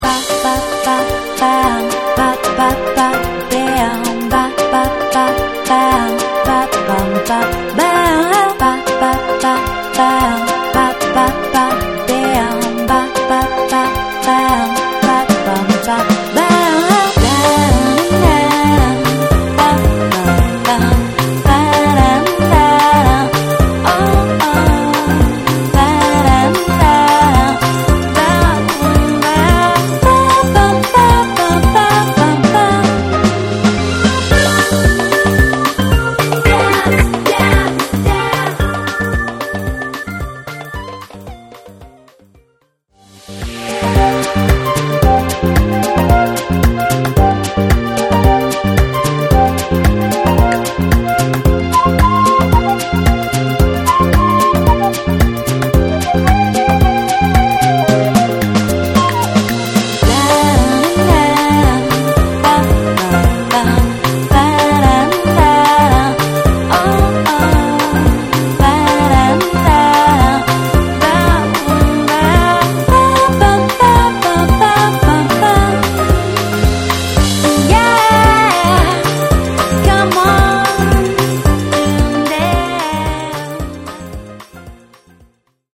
UN MIX CON SONORITA’ FUNKY, BRASIL, DISCO E NU JAZZ